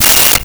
Cell Phone Ring 13
Cell Phone Ring 13.wav